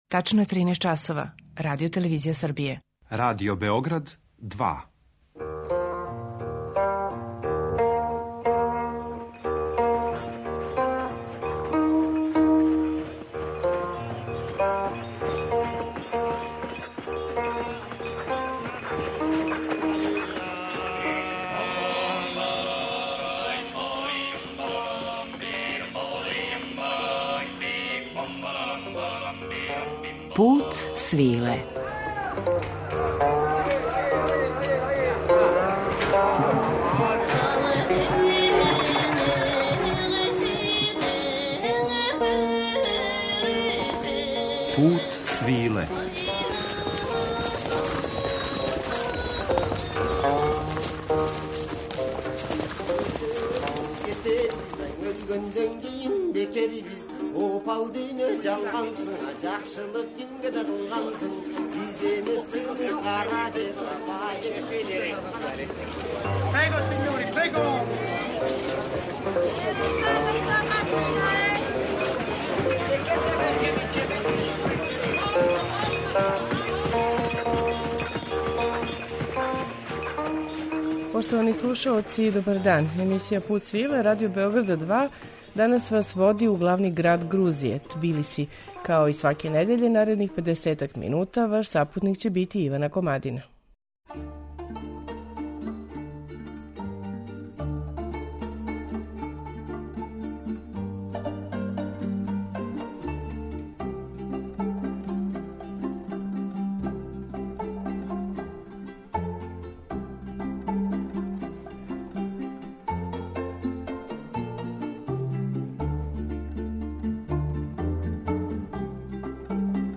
Пут свиле, као јединствено “радијско путовање”, недељом одводи слушаоце у неку од земаља повезаних са традиционалним Путем свиле, уз актуелна остварења из жанра “World music” и раритетне записе традиционалне музике.
У данашњем Путу свиле те бајковите делове Тбилисија обићи ћемо у музичком друштву квинтета Урмули, женске групе Затемзи, ансамбла Басани и хора цркве Анчишати.